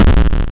BigDeath.wav